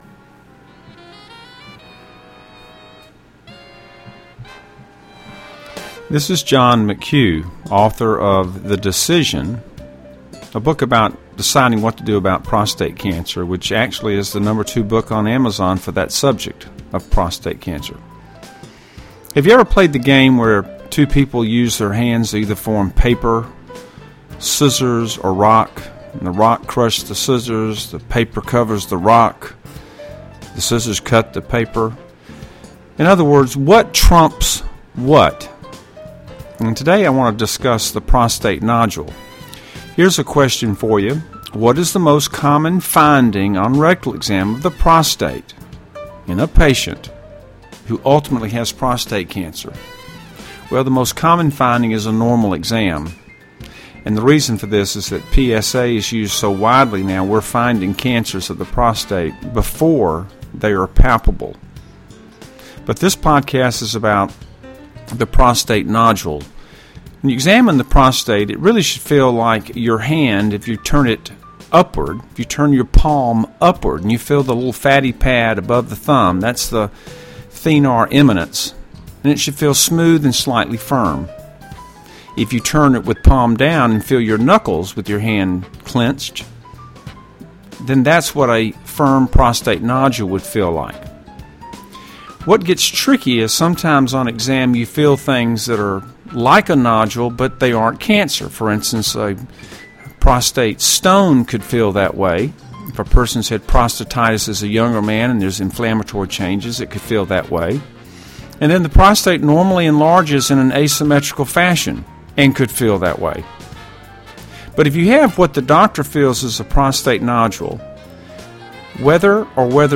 The little thing at the end is a verse from a song of one of my favorite bands…do you recognize it?